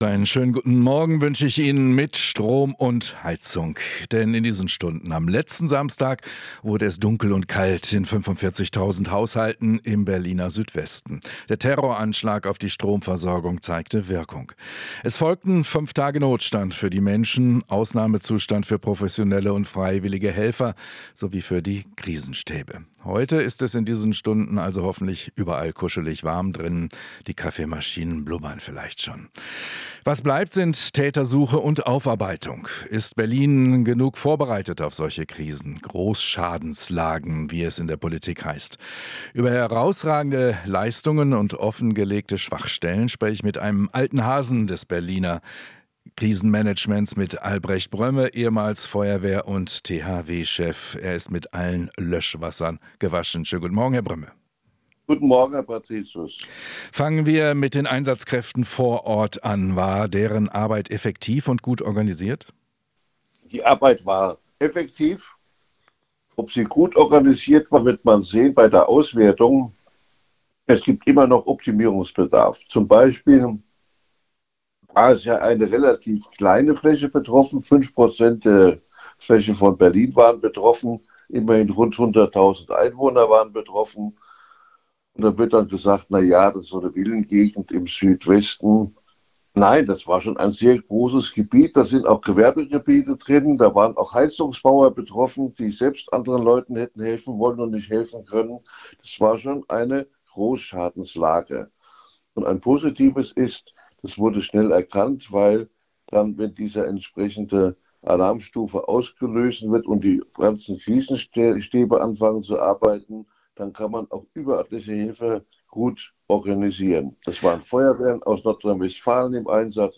Experte zu Stromausfall: Wegner (CDU) war gut informiert